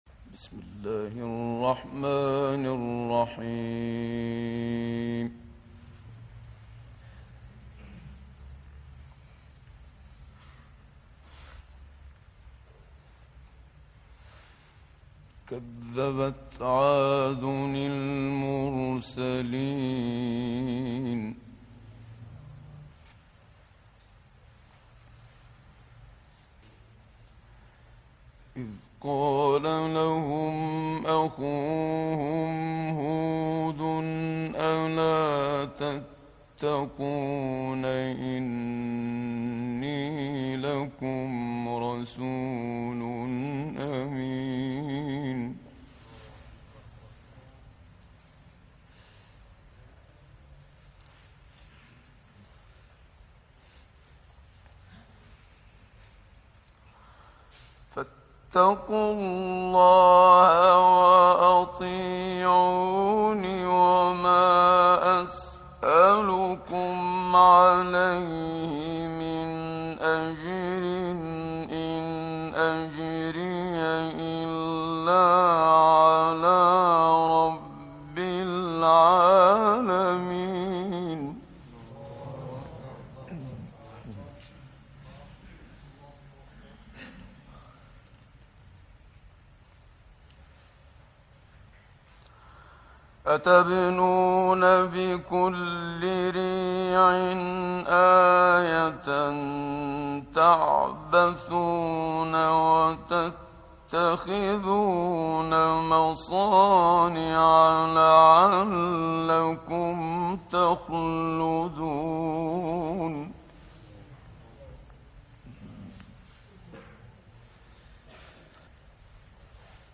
صوت| قرائت کمتر شنیده شده «منشاوی» از سوره شعراء
گروه چندرسانه‌ای ــ تلاوت آیات ۱۲۳ تا ۲۲۷ سوره مبارکه شعراء با صوت شیخ محمد صدیق منشاوی، قاری بنام جهان اسلام را می‌شنوید. این تلاوت کمتر شنیده شده در کشور لیبی اجرا شده است.